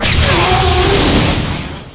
fire_no_capship.wav